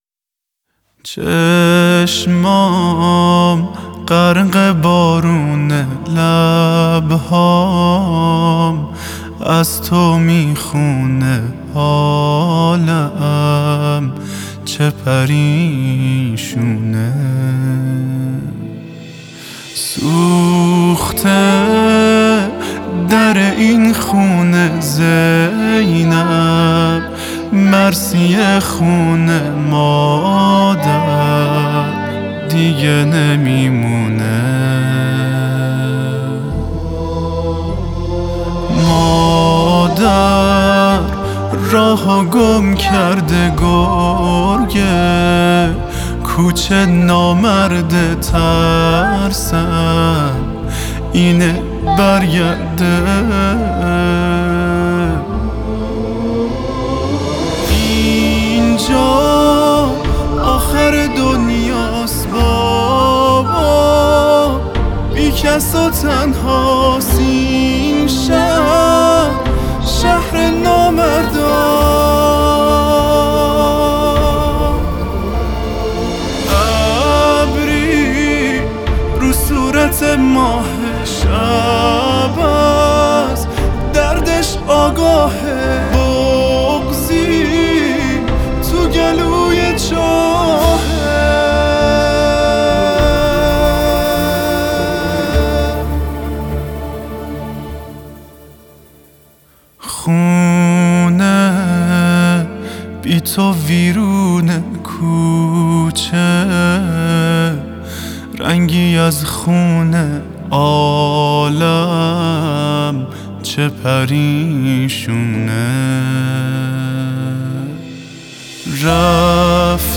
دانلود مداحی بغض - دانلود ریمیکس و آهنگ جدید
ویژه ایام فاطمیه سلام الله علیها